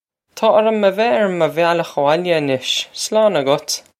Pronunciation for how to say
Taw orr-um veh air muh vyal-okh a-wohl-ya ah-nish. Slawn a-gut!
This is an approximate phonetic pronunciation of the phrase.